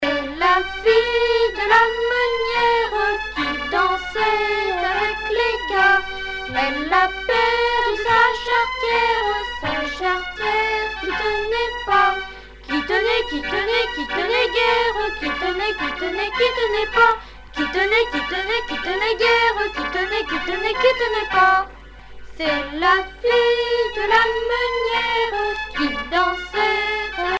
Chants brefs - A danser
Pièce musicale éditée